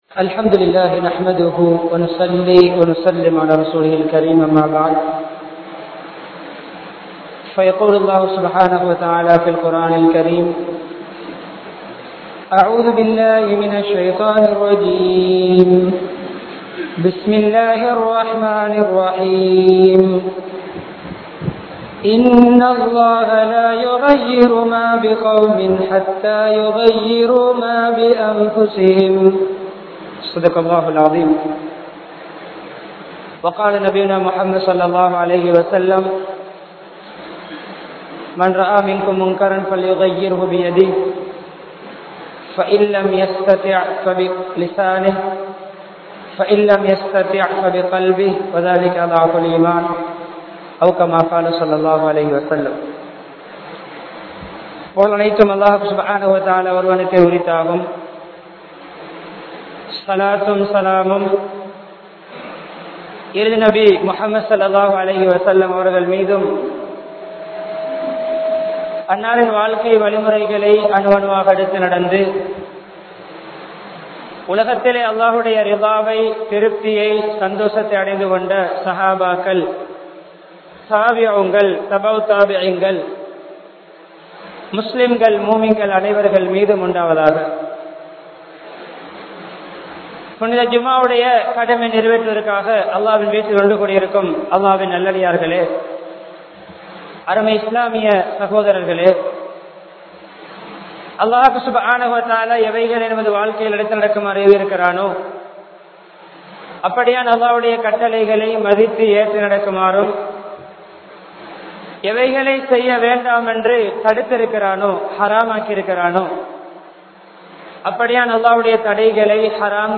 Theeya Palakkangalai Vittu Vidungal (தீய பழக்கங்களை விட்டு விடுங்கள்) | Audio Bayans | All Ceylon Muslim Youth Community | Addalaichenai
Muhammed Town Jumua Masjidh